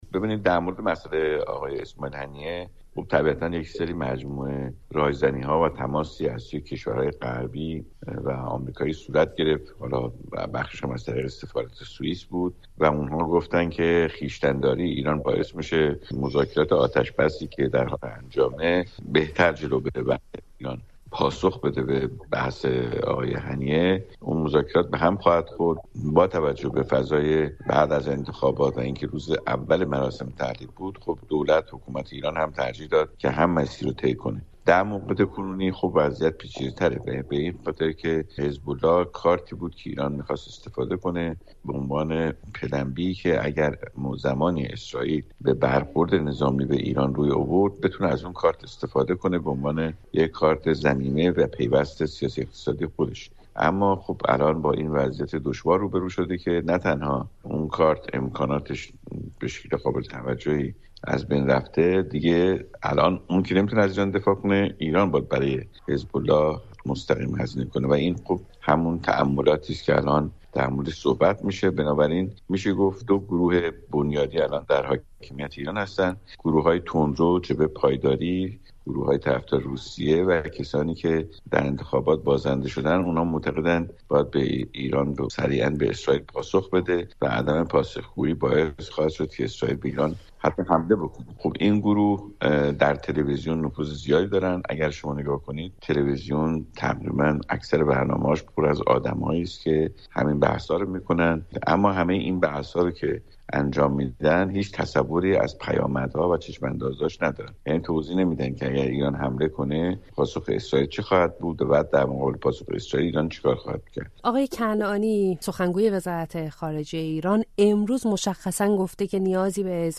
تحلیلگر سیاسی